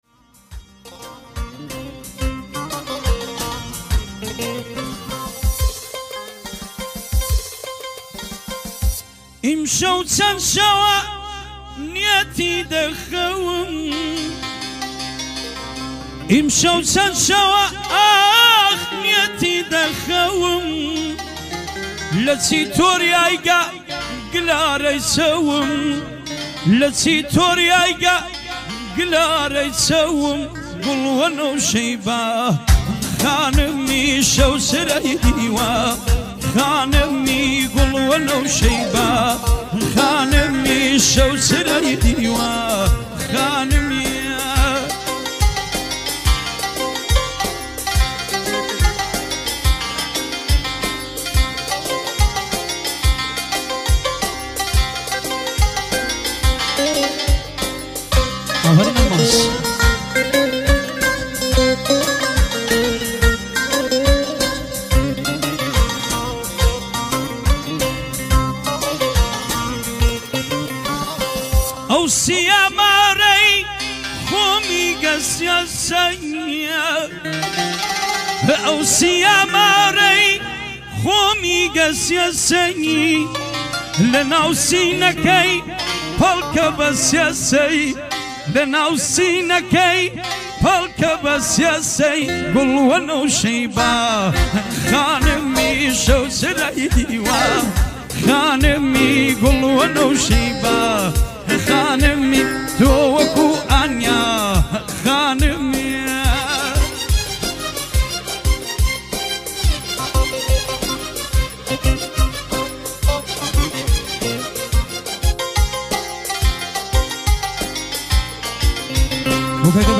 ترانه کردی قدیمی و مجلسی